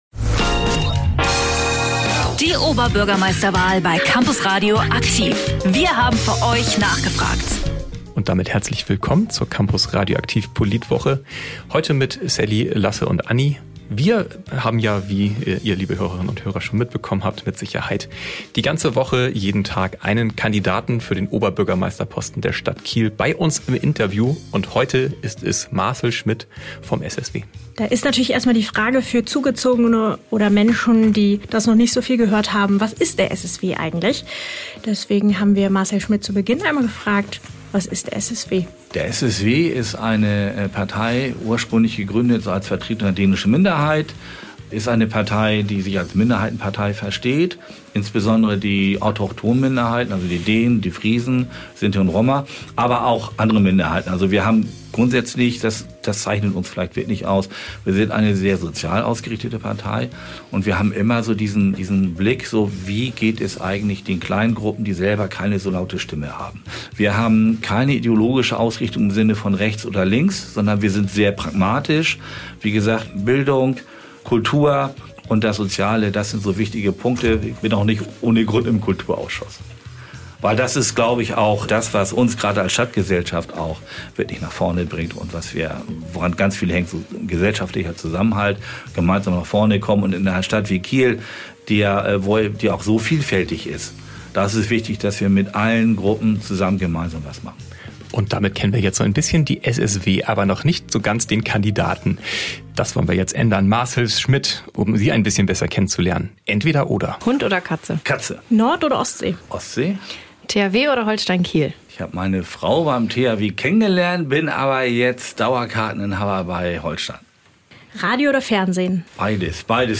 Moin! Wir von Campus RadioAktiv haben uns für die Oberbürgermeisterwahl am 16.11.2025 einige der Kandidaten zu uns ins Studio eingeladen.